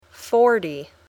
forty.mp3